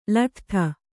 ♪ laṭhṭhaa